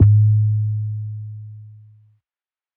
TC 808 7.wav